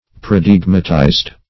Search Result for " paradigmatized" : The Collaborative International Dictionary of English v.0.48: Paradigmatize \Par`a*dig"ma*tize\, v. t. [imp.